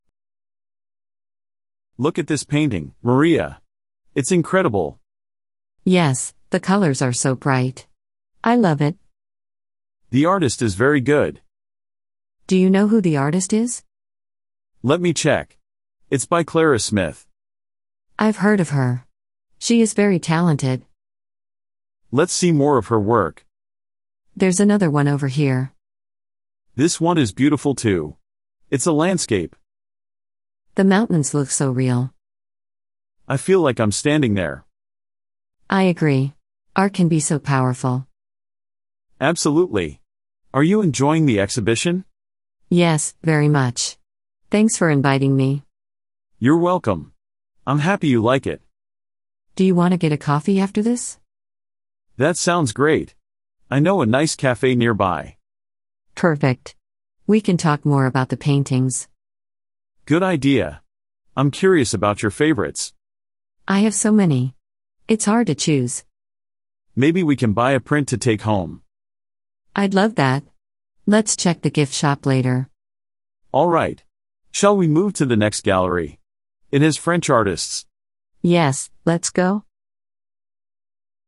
Beginner Listening Practice
Two friends explore an art exhibition and share their thoughts about the paintings.